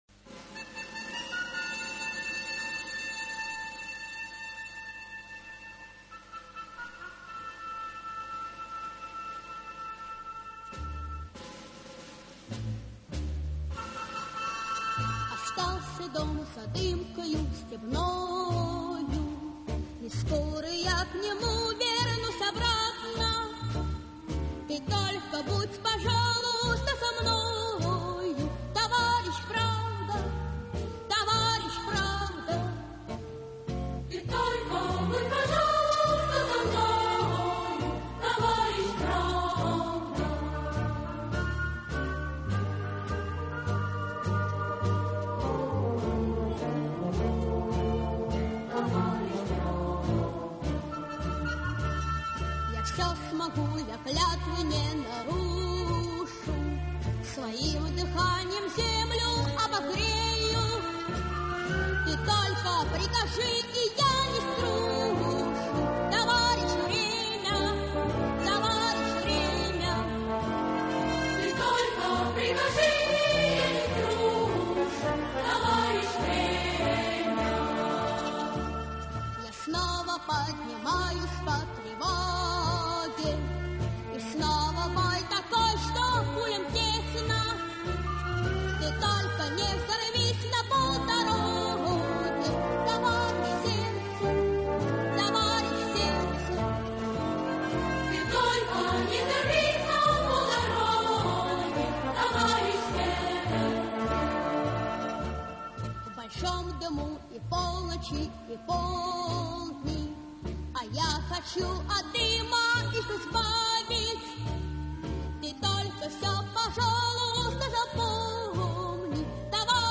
в исполнении детского хора